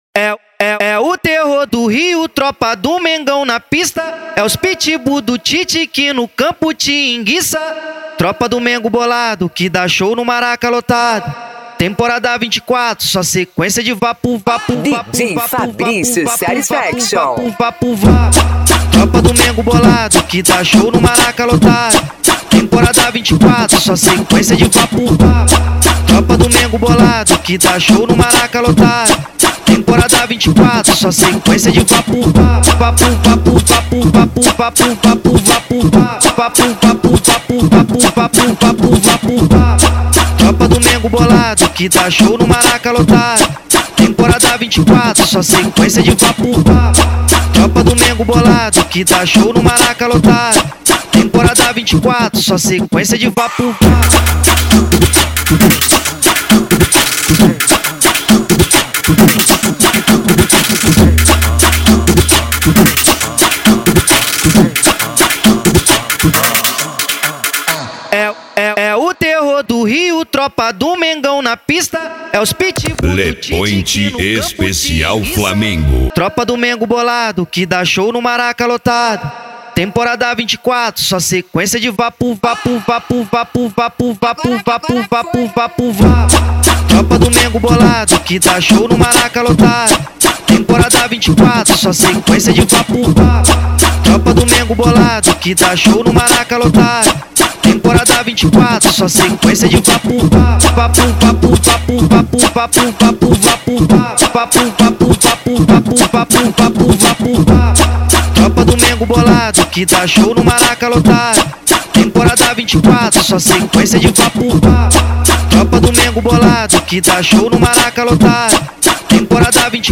Funk
Mega Funk